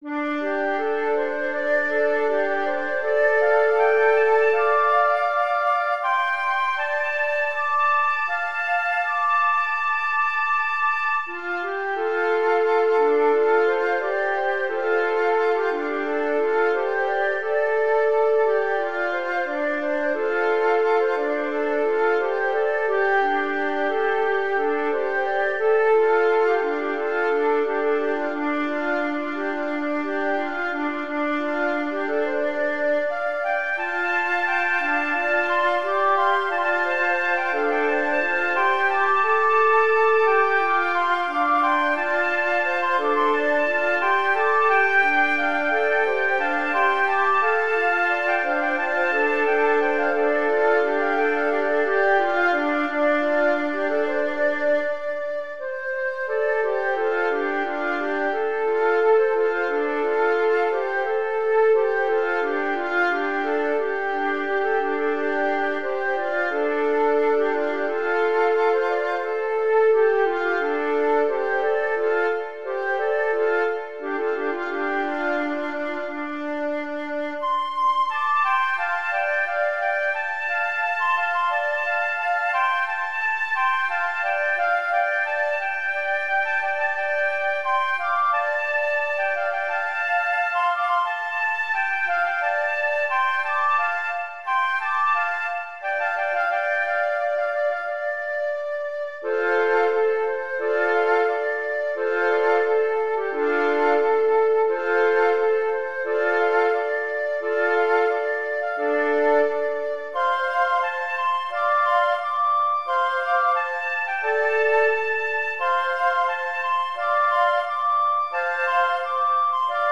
尺八1（d）
尺八2（d）
尺八3（d）